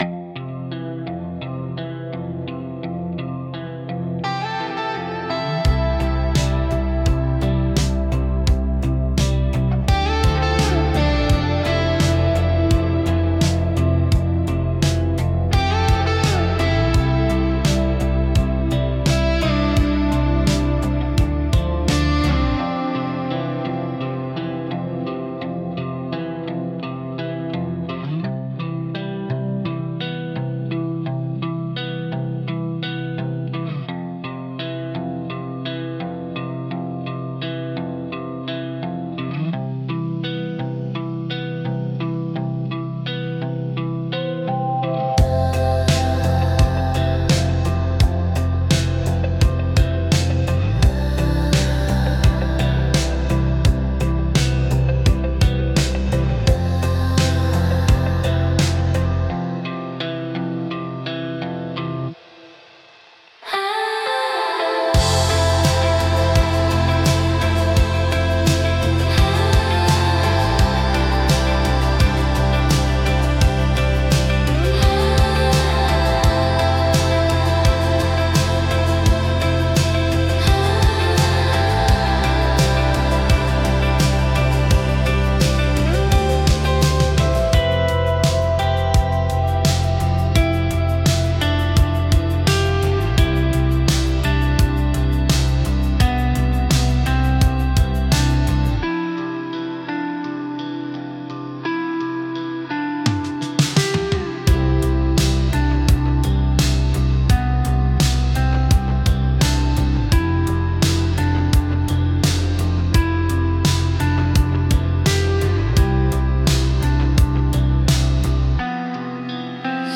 BGMセミオーダーシステムドリームポップは、繊細で幻想的なサウンドが特徴のジャンルです。
静かで美しい音の重なりが心地よく、感性を刺激しながらも邪魔にならない背景音楽として活用されます。